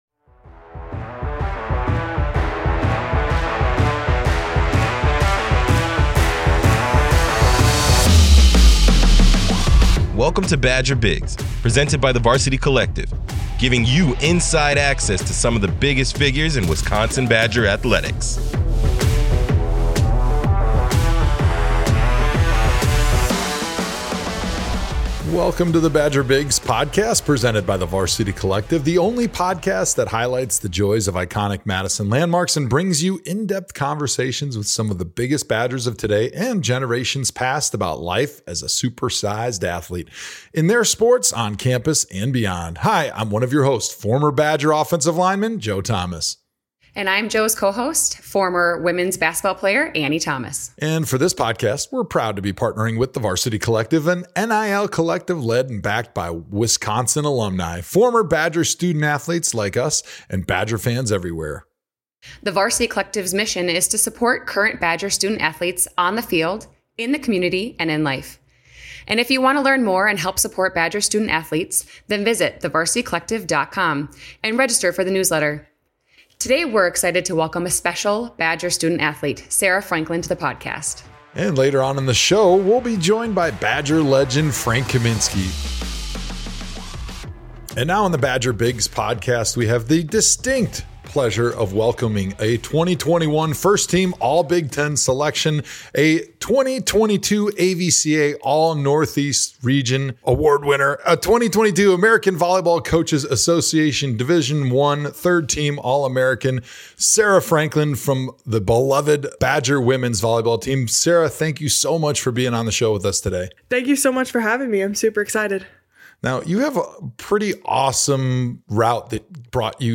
have conversations with some of the biggest Badgers of today and generations past about life as a super-sized athlete